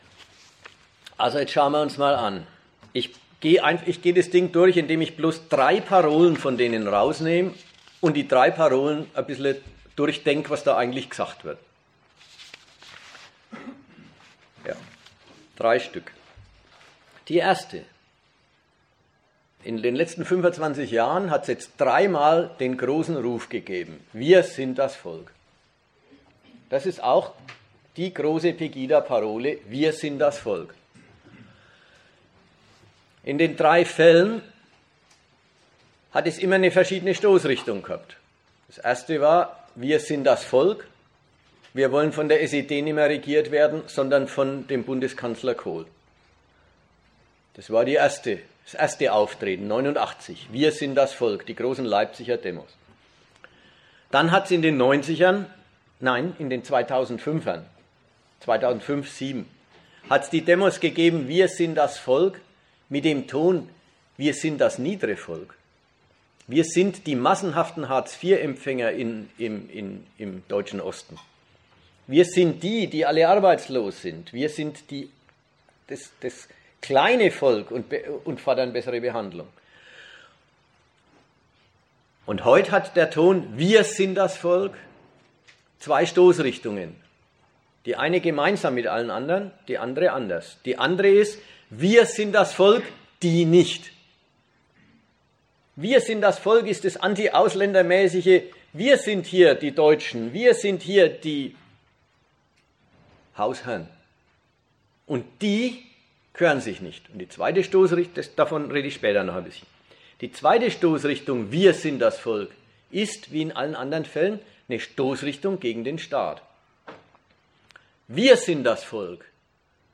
Unsere Vortrags- und Diskussionsveranstaltung mit Redakteuren der Zeitschrift GegenStandpunkt soll Antworten hierzu liefern.